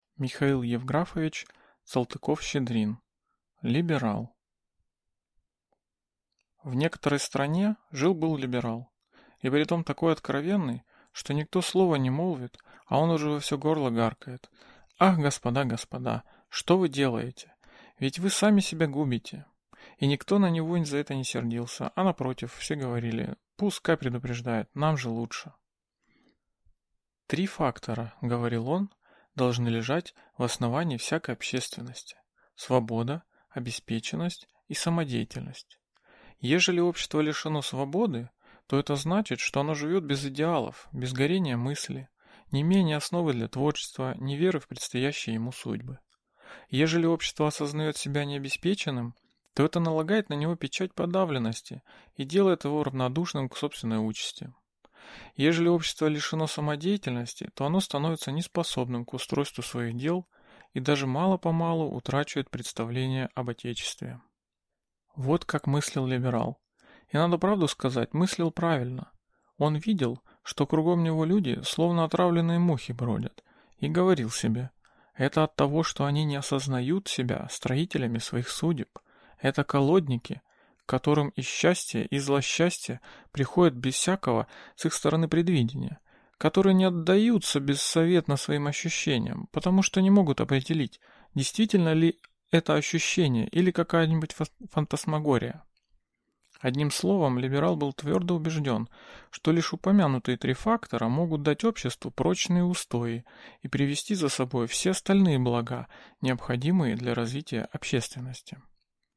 Аудиокнига Либерал | Библиотека аудиокниг